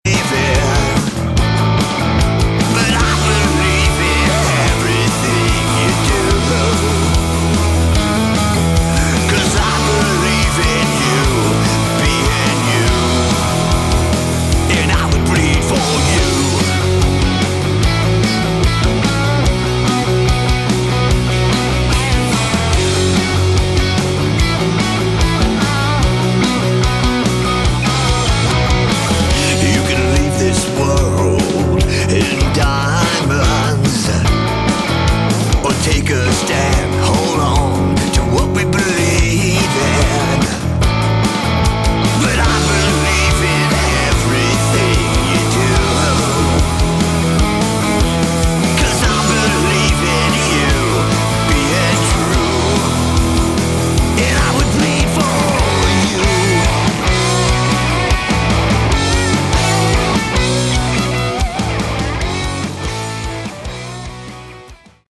Category: Sleaze Glam/Punk
lead vocals, guitars
bass, piano, mandolin, percussion, backing vocals
drums, backing vocals
guitar, ebow, backing vocals
acoustic guitar, electric steel, backing vocals